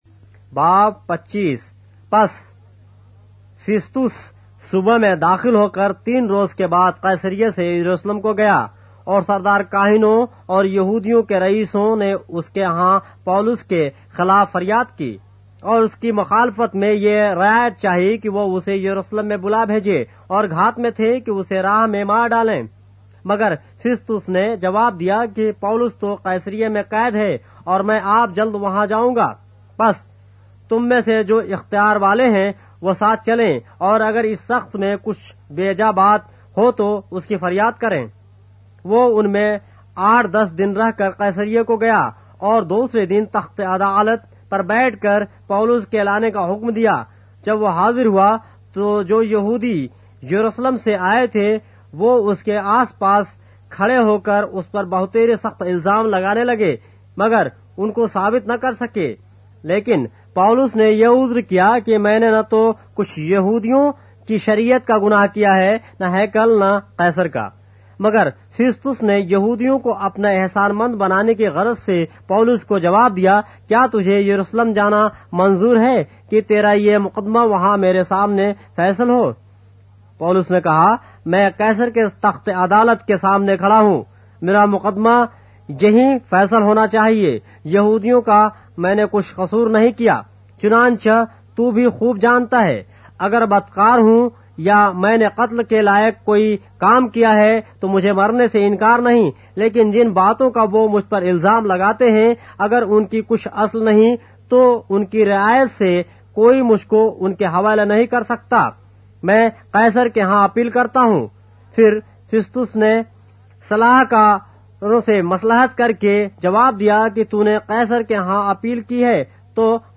اردو بائبل کے باب - آڈیو روایت کے ساتھ - Acts, chapter 25 of the Holy Bible in Urdu